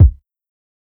KICK_COMINGGG.wav